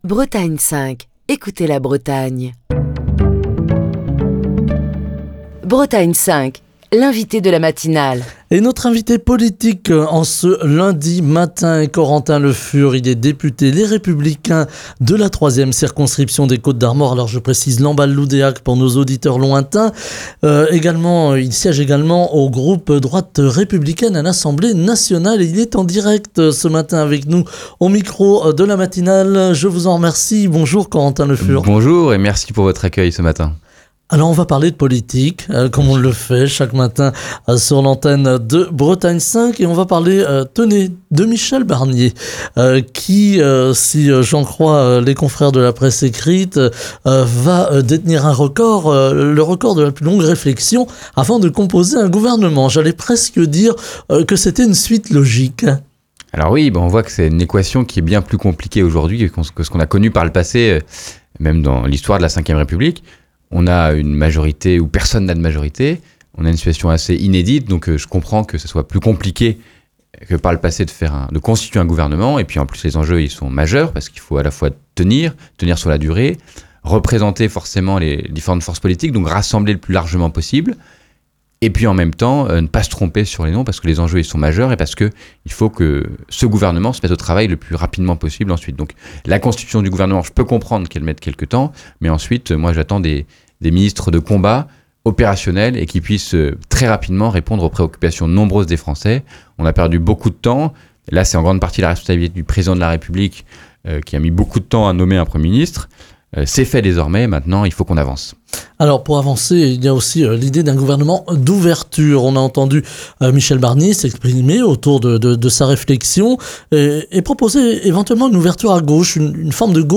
Ce lundi, nous évoquons la situation politique avec Corentin Le Fur, député Les Républicains de la 3e circonscription des Côtes d’Armor (Lamballe-Loudéac), qui est l'invité de Bretagne 5 Matin.